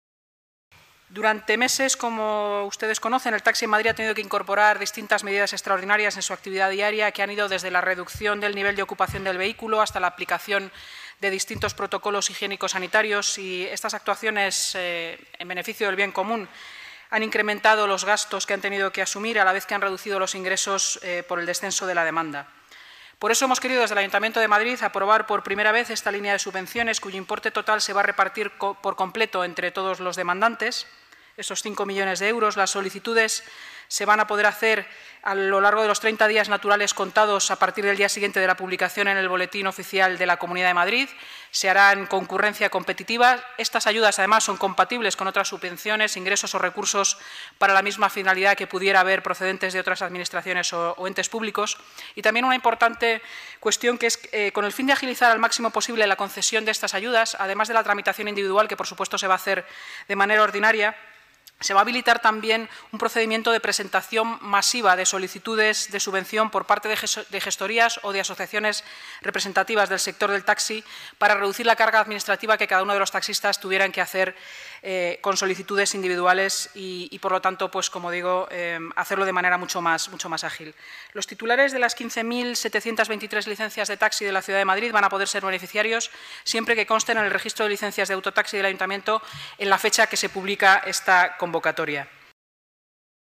Nueva ventana:Inmaculada Sanz, portavoz municipal